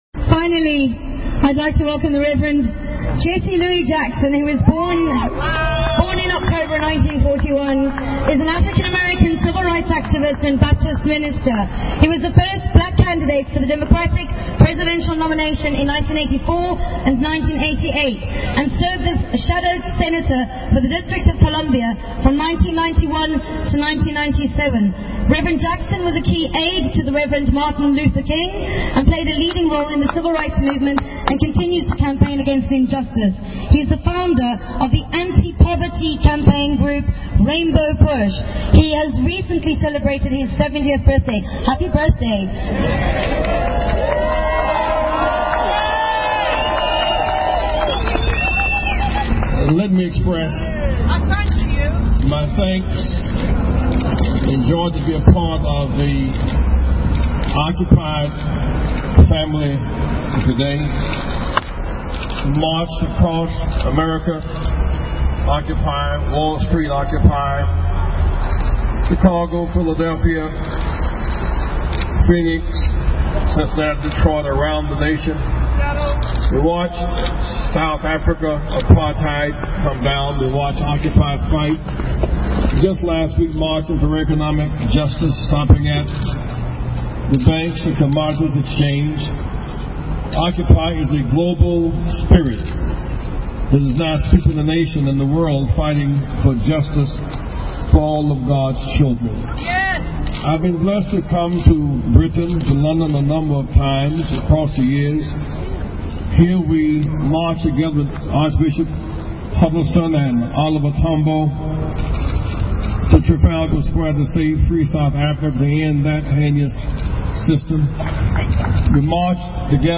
Here is the full speech - some encouraging liberation theology and a rather pushy people´s microphone performance.